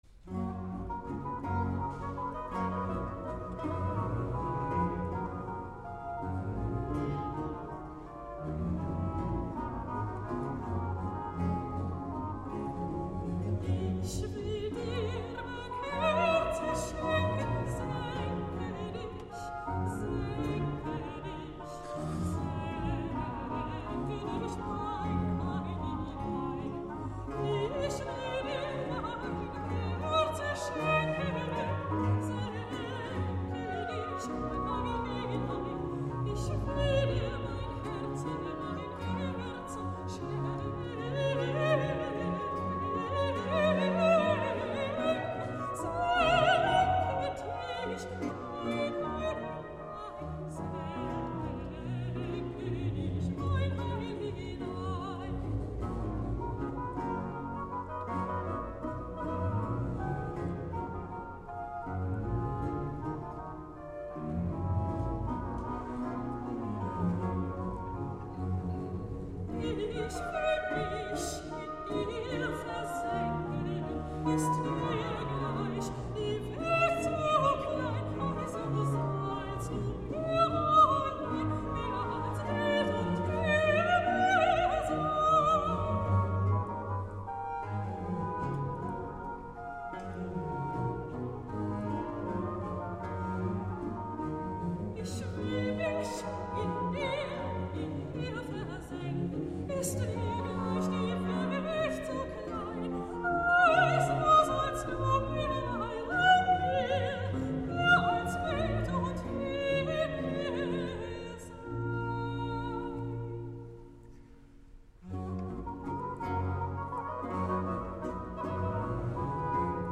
Sopran
J.S. Bach: Ich will Dir mein Herze schenken, Matthäus-Passion (Live-Mitschnitt)